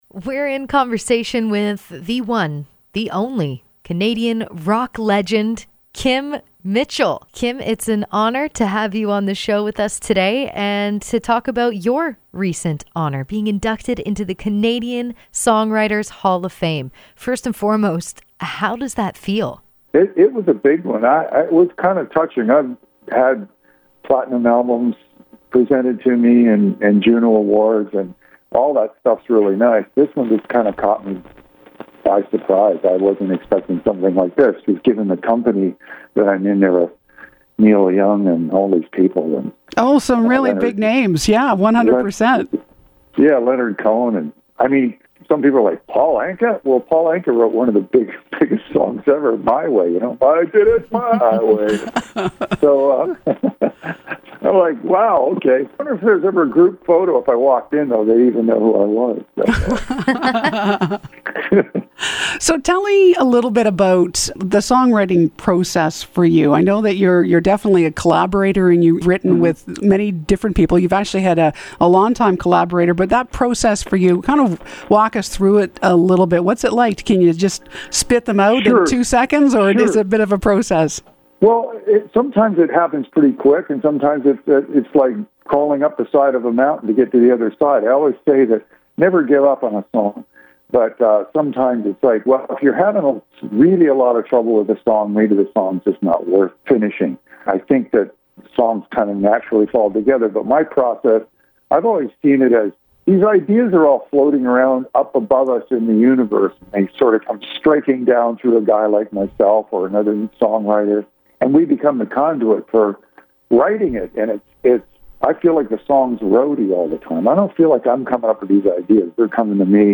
Kim was kind enough to join us on Max Mornings to talk all about this incredible honour, his songwriting process, and about his latest record, “The Big Fantasize”.